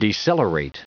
Prononciation du mot decelerate en anglais (fichier audio)
Prononciation du mot : decelerate